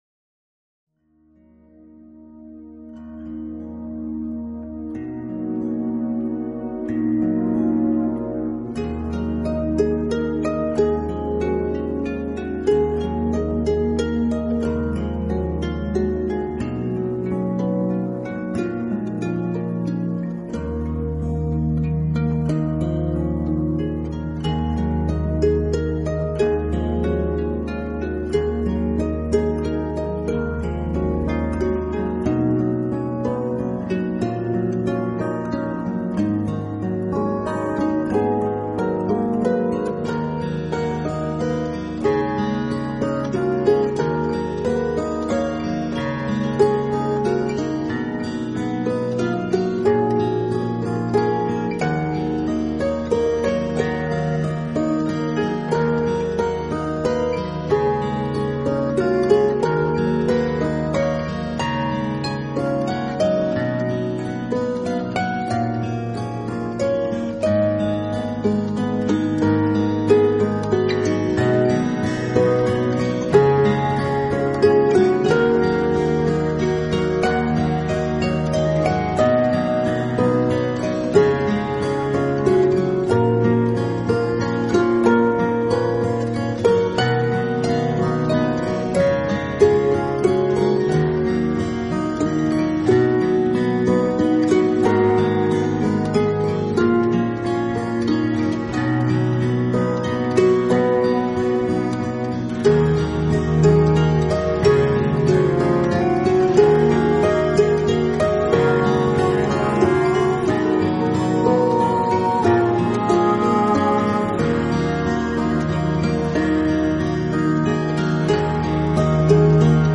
通过New Age与爱尔兰